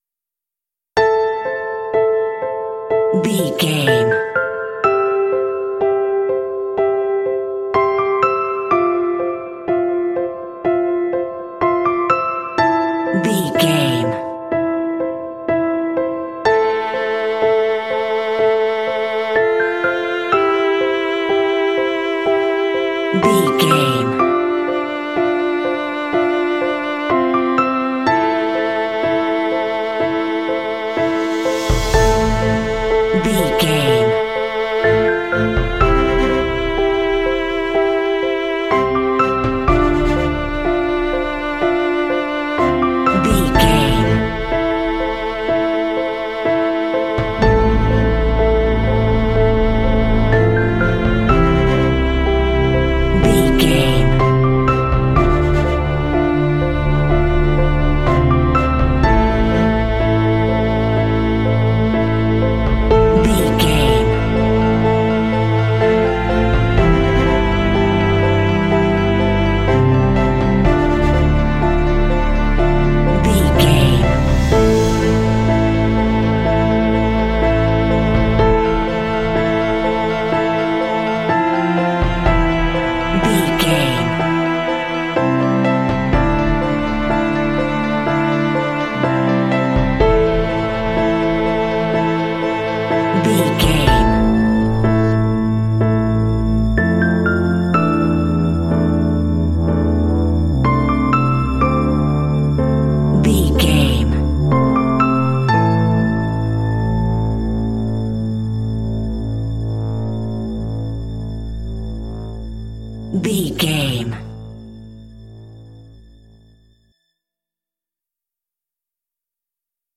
Thriller
Aeolian/Minor
Slow
melancholy
mournful
foreboding
tension
contemplative
piano
strings
acoustic guitar
drums
cinematic